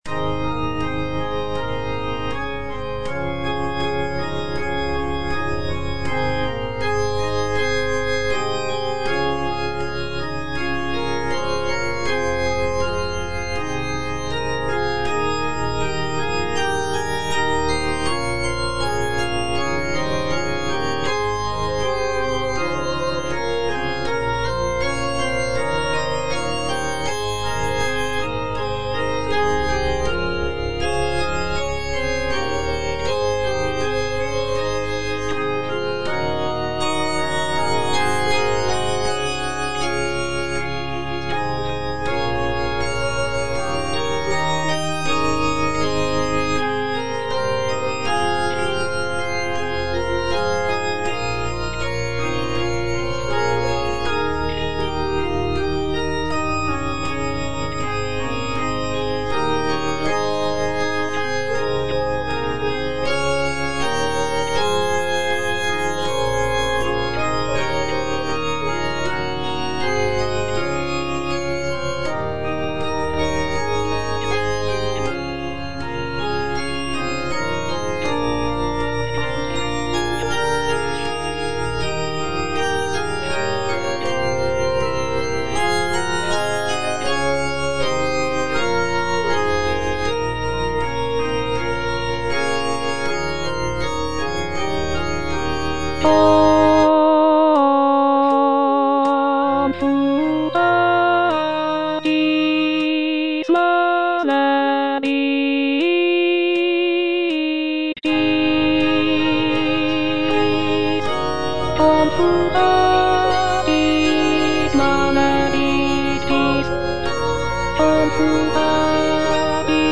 Alto (Voice with metronome) Ads stop
is a sacred choral work rooted in his Christian faith.